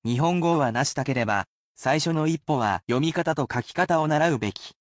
You can repeat after the word pronunciation, but the sentences are at regular speed in order to acclimate those learning to the pace.